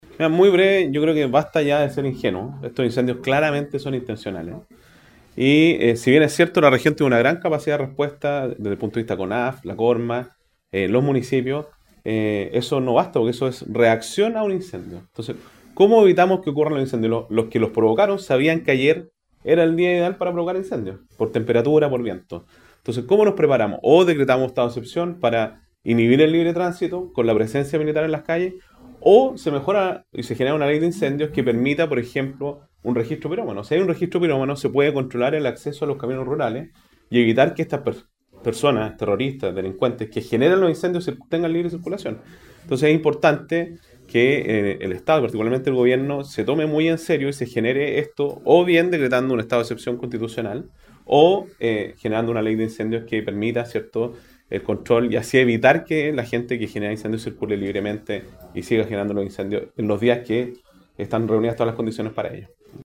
Finalmente, el gobernador Sergio Giacaman, acusó de que se trataría de siniestros intencionales y llamó a apresurar la aprobación de normativas como la Ley de Incendios actualmente en trámite.